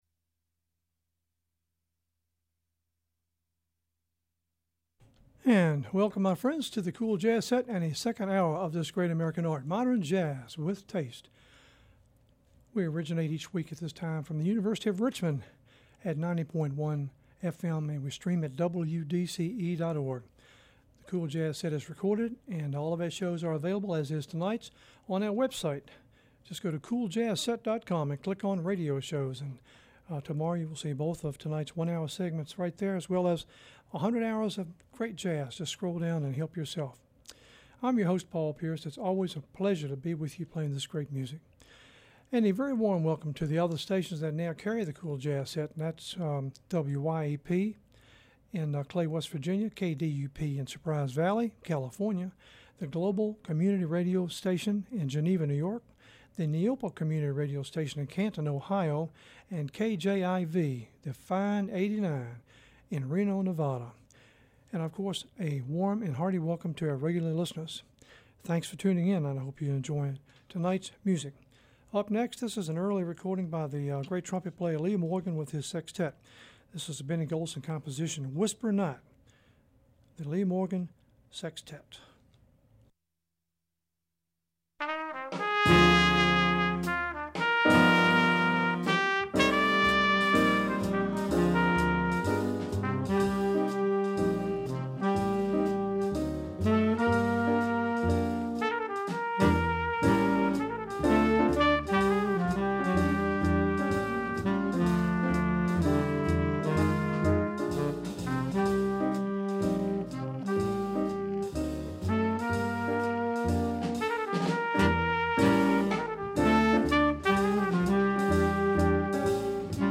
cool jazz set